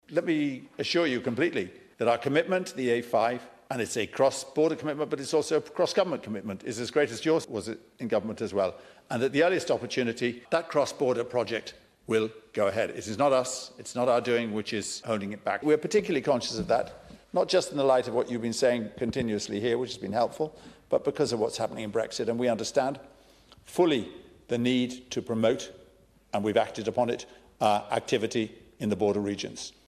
The Transport Minister has told the Dail that while uncertainty over a start date for the A5 remains, the Irish Government’s funding commitment is steadfast.
Minister Ross in response says the Government is fully committed to supporting the Western Transport Scheme coming to fruition as soon as possible: